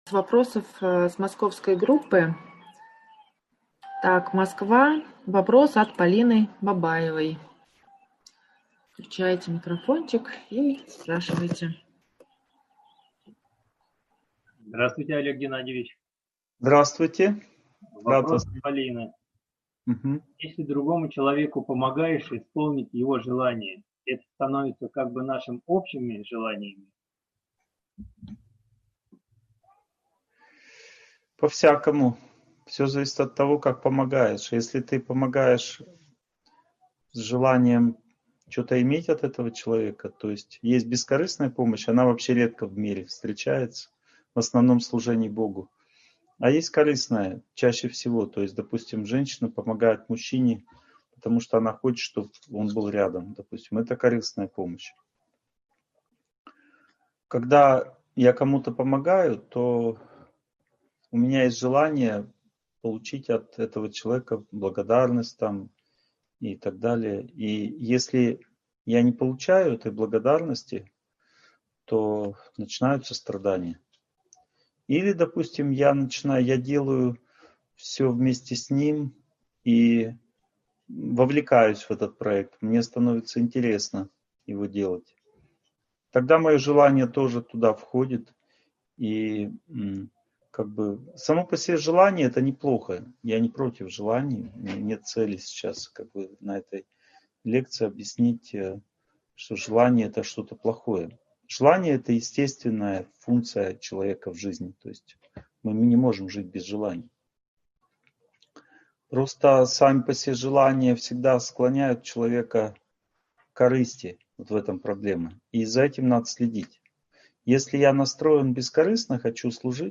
Желания и их природа (онлайн-семинар, 2020)